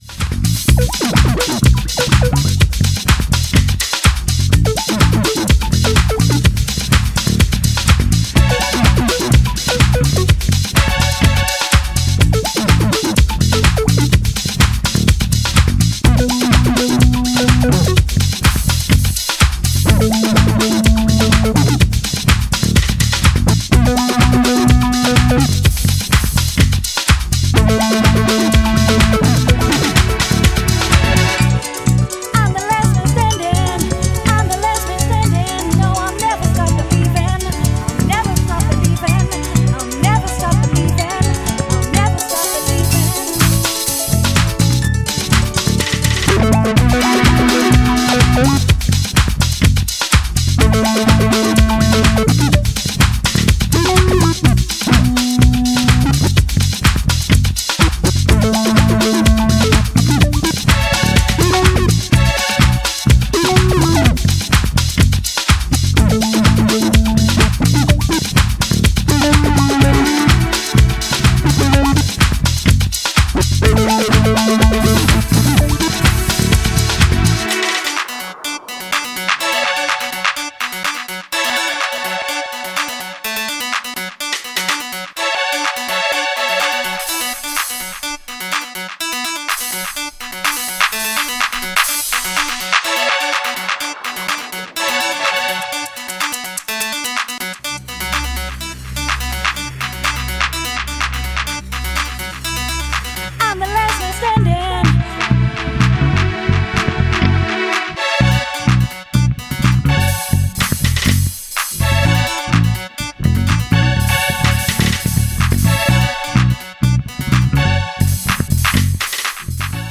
“Very funky!”